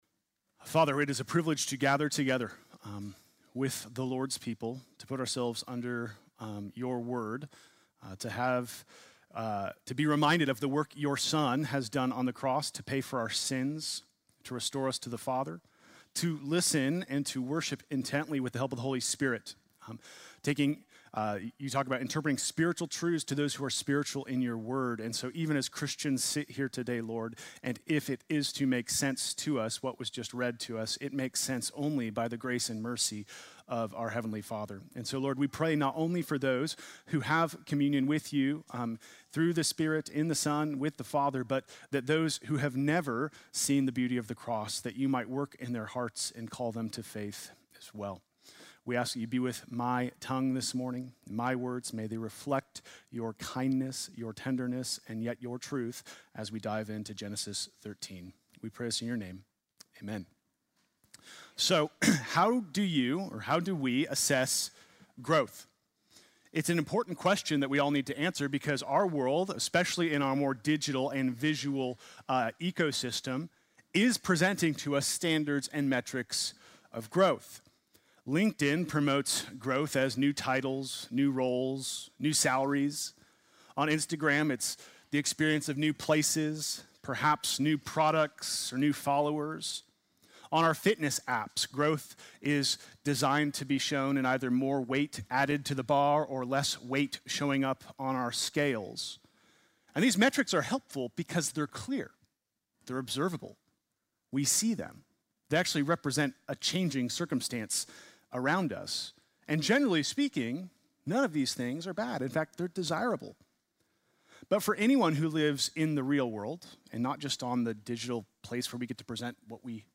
Sunday morning message February 1, 2026